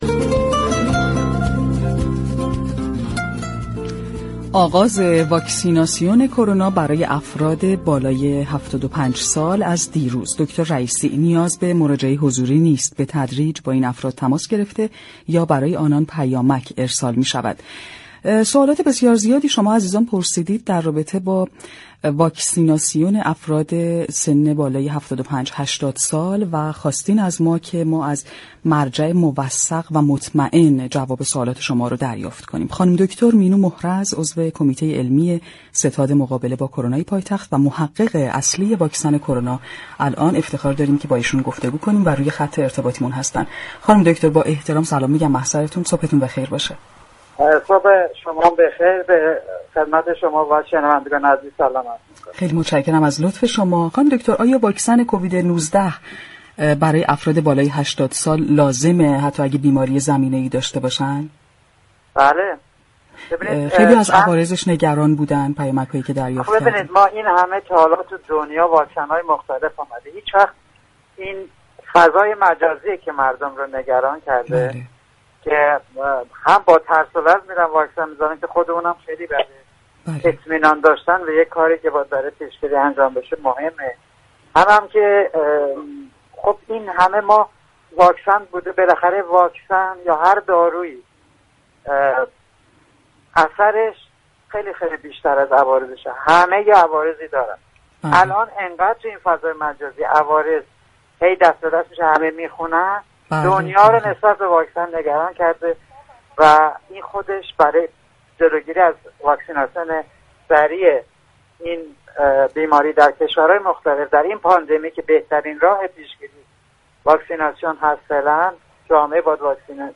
در گفتگو با برنامه «تهران ما سلامت» رادیو تهران